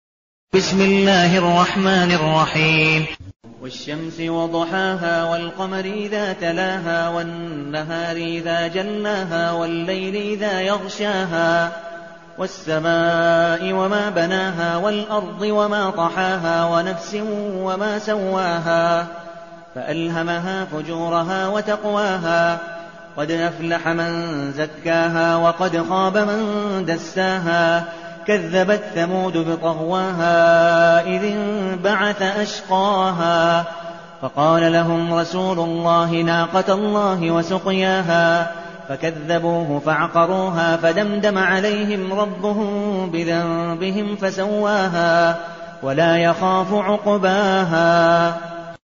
المكان: المسجد النبوي الشيخ: عبدالودود بن مقبول حنيف عبدالودود بن مقبول حنيف الشمس